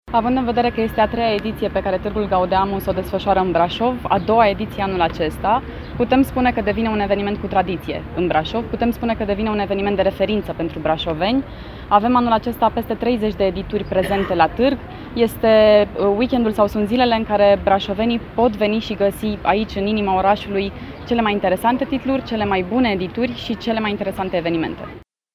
Viceprimarul Flavia Boghiu, la deschiderea evenimentului: